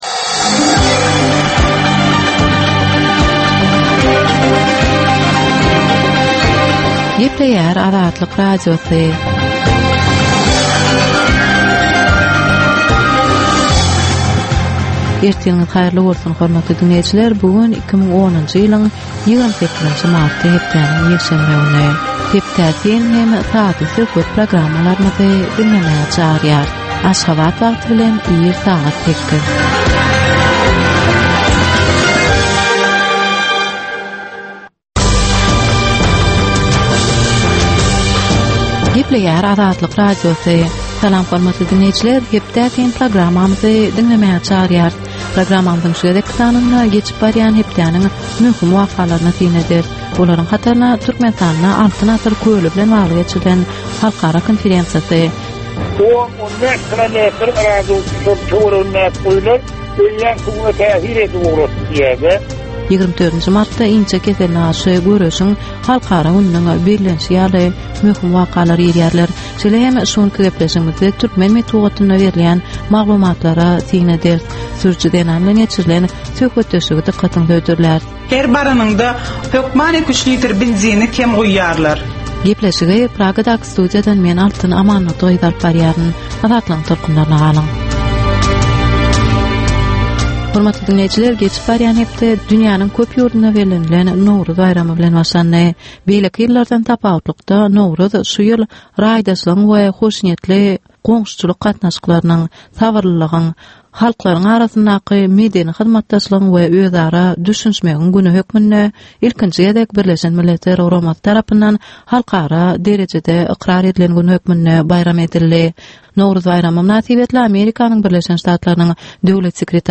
Tutuş geçen bir hepdäniň dowamynda Türkmenistanda we halkara arenasynda bolup geçen möhüm wakalara syn. 30 minutlyk bu ýörite programmanyň dowamynda hepdäniň möhüm wakalary barada gysga synlar, analizler, makalalar, reportažlar, söhbetdeşlikler we kommentariýalar berilýär.